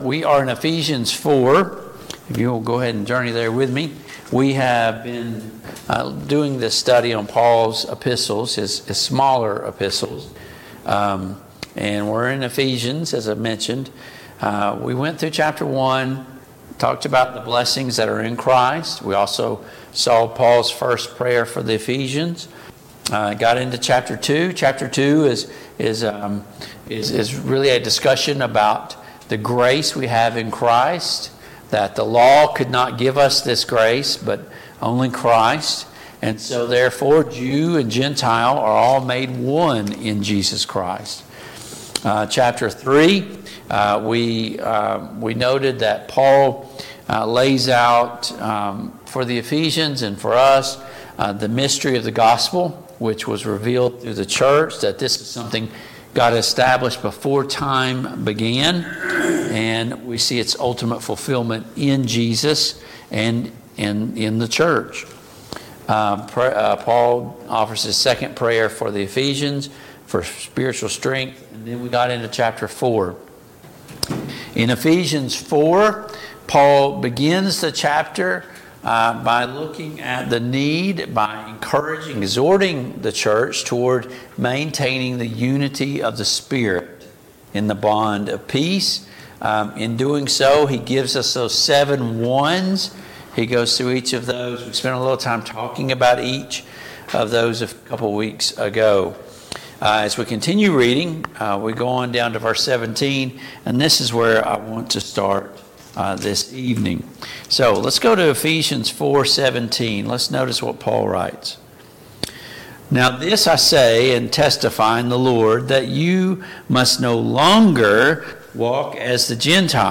Passage: Ephesians 4:17-32 Service Type: Mid-Week Bible Study Download Files Notes « 22.